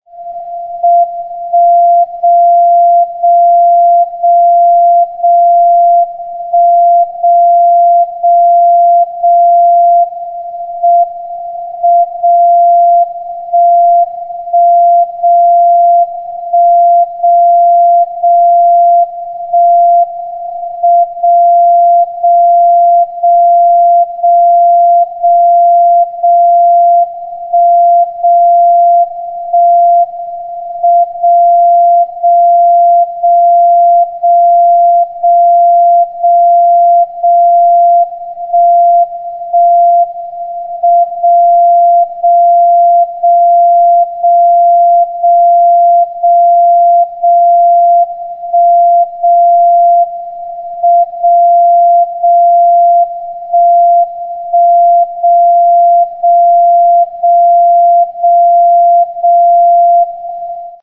60 KHZ WWVB TIME SIGNAL AUDIO
This is the signal all those "Atomic Clocks" use to synchronize themselves to WWV. Transmitting the data for a single time frame takes a full minute! This audio clip contains the full synchronization sequence.